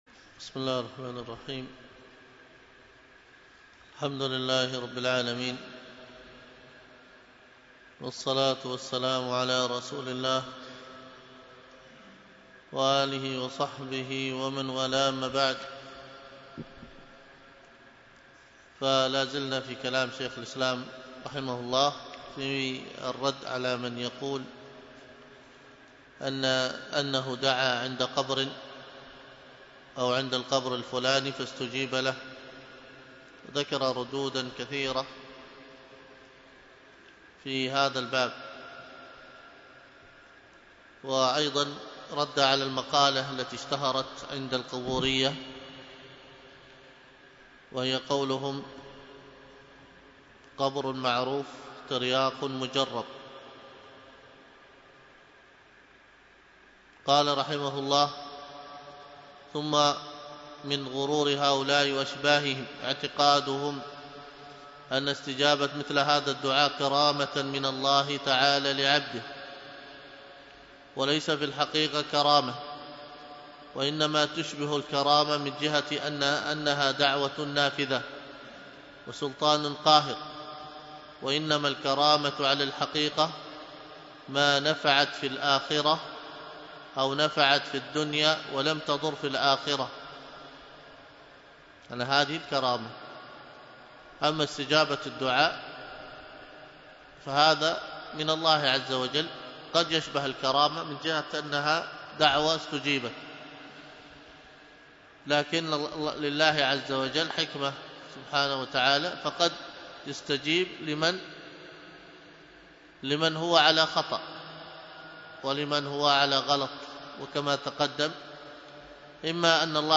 الدرس في مقتطفات من جامع بيان العلم وفضله 4، ألقاها